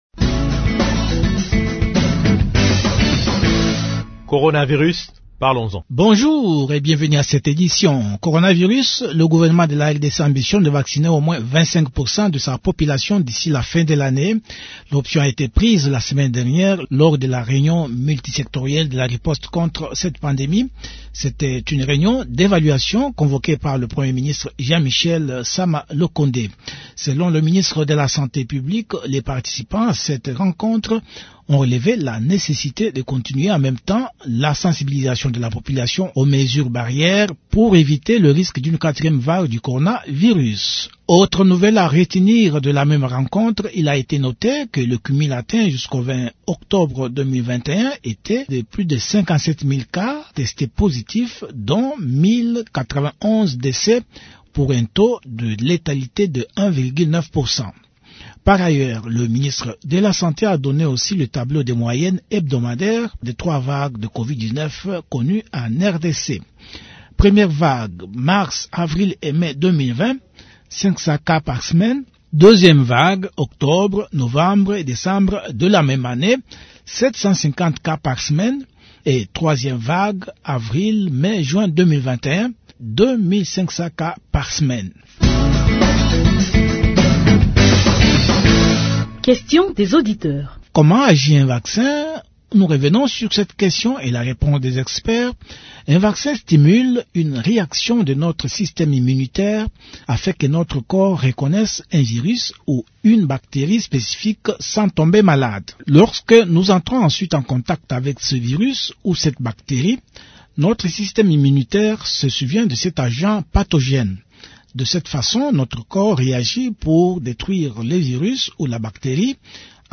Actualité